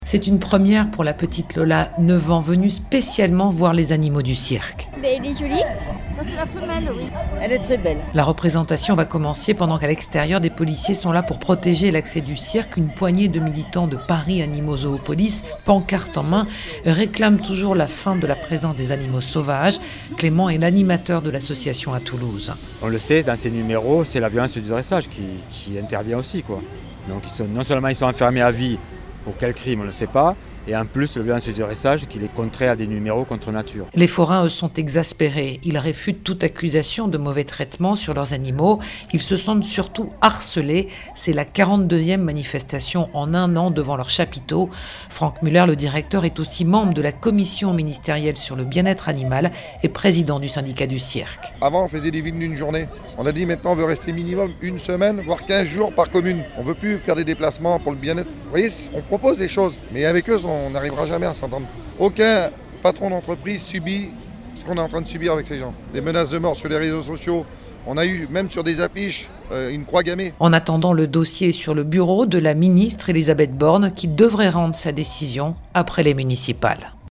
La représentation démarre alors que des policiers sont postés à l'extérieur pour protéger l'accès du cirque : une poignée de militants de l'association Paris Animaux Zoopolis, pancartes en mains, clament la fin de la présence d'animaux sauvages.